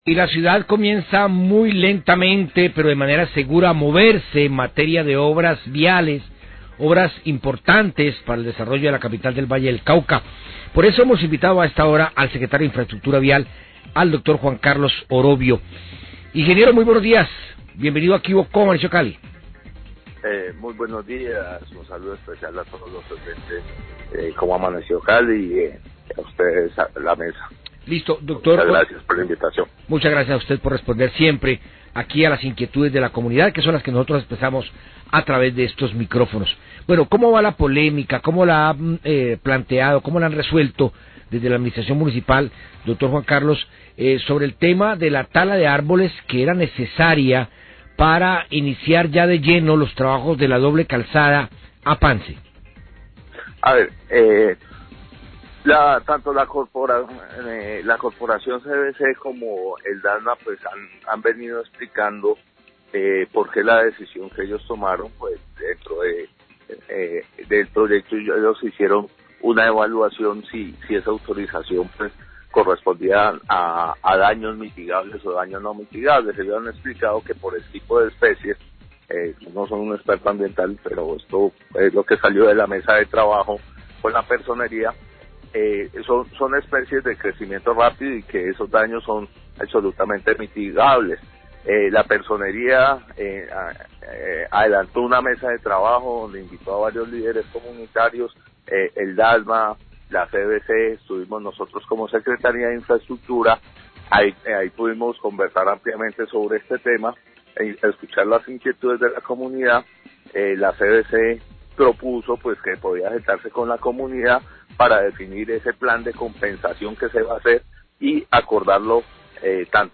SECRETARIO DE INFRAESTRUCTURA SE REFIERE A OBRAS EN LA VÍA A PANCE, 6-44AM
Radio